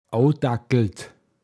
Wortlisten - Pinzgauer Mundart Lexikon
abgetakelt, heruntergekommen åotagglt